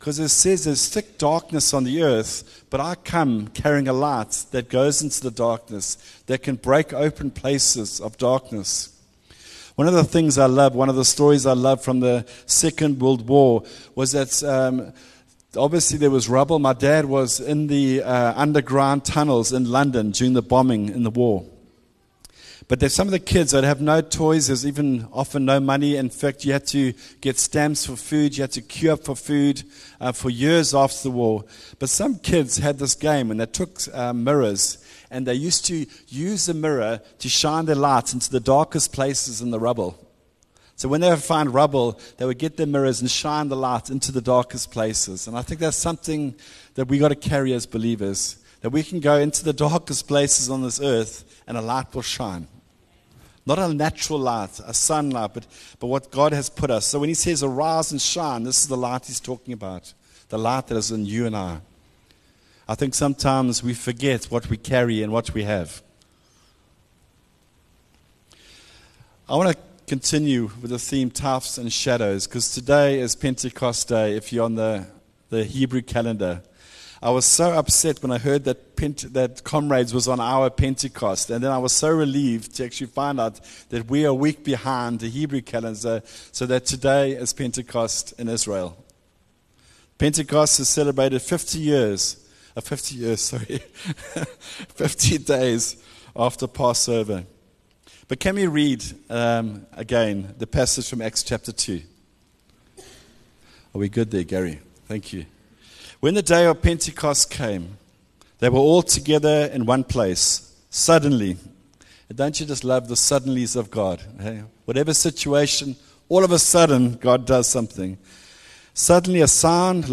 View Promo Continue JacPod Install Upper Highway Vineyard Sunday messages 3 Jun One New Hope 23 MIN Download